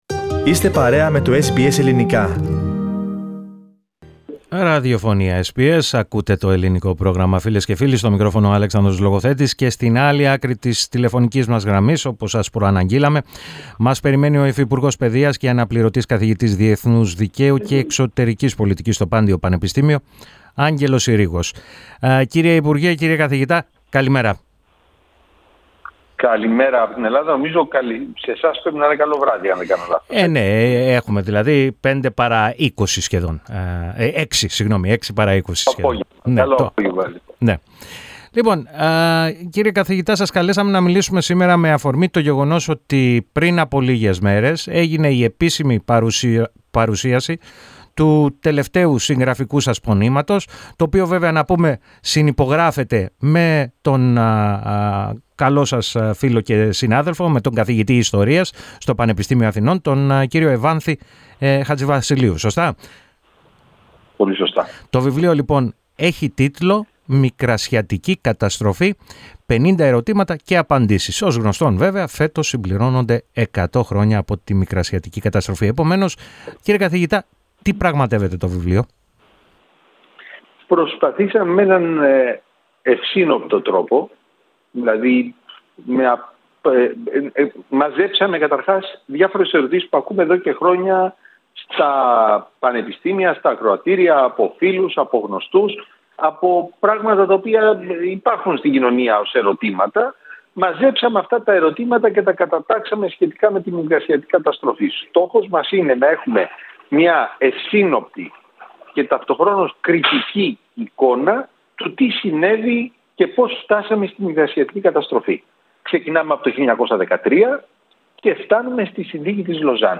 Ο υφυπουργός Παιδείας, και Αναπληρωτής καθηγητής Διεθνούς Δικαίου και Εξωτερικής Πολιτικής στο Πάντειο Πανεπιστήμιο, Άγγελος Συρίγος, μίλησε στο Ελληνικό Πρόγραμμα της ραδιοφωνίας SBS, με αφορμή την έκδοση του νέου του βιβλίου.